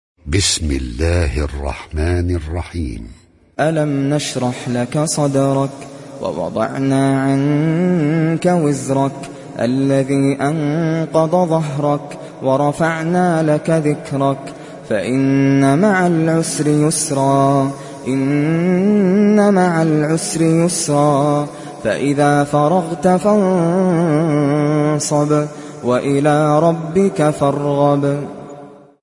Surat Ash Sharh mp3 Download Nasser Al Qatami (Riwayat Hafs)